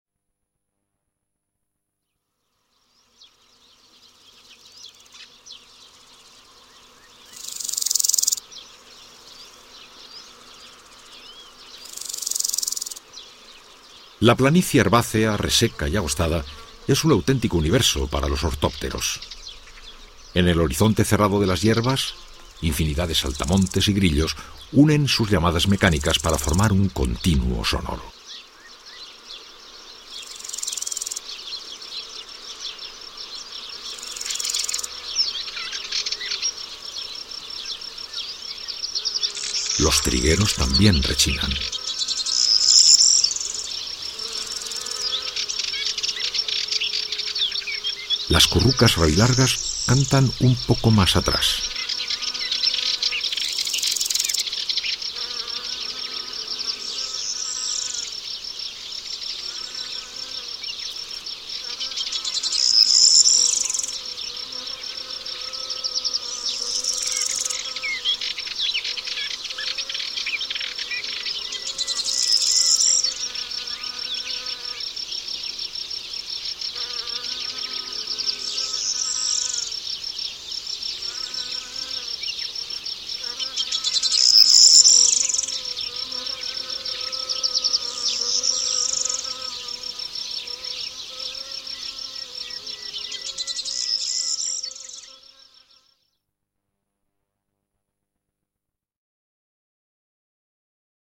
El Sonido de los Parques Nacionales es una colección realizada en el año 2003 por Carlos de Hita con las narraciones de Iñaki Gabilondo.
Agosto, mar de insecto en el herbazal, mediodía (01:37)